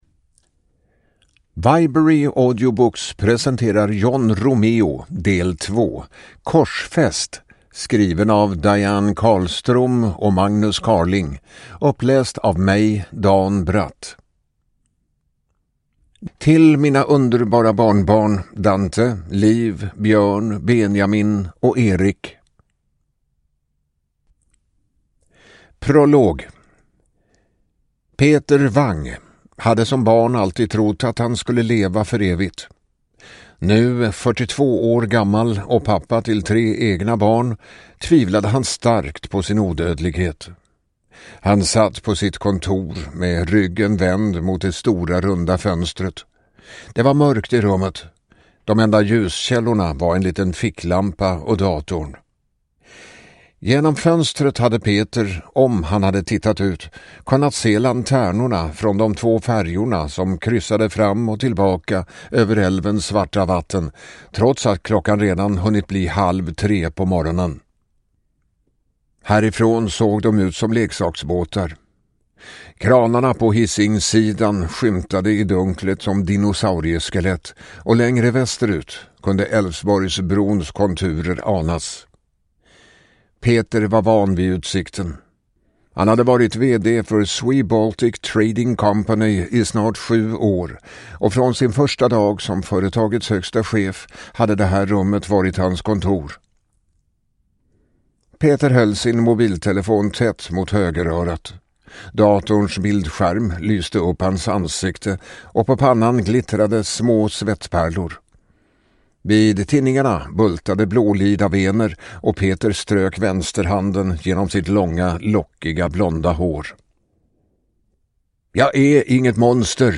Ljudbok